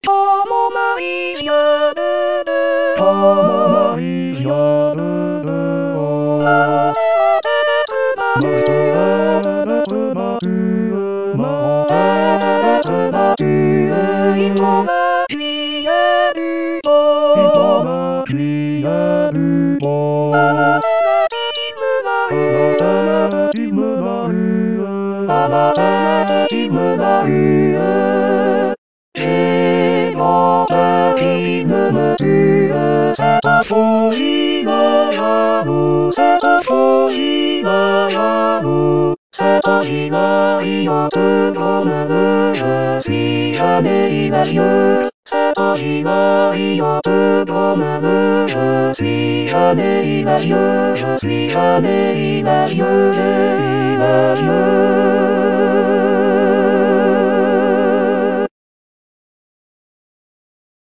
Quand-mon-mari-00-Choeur.mp3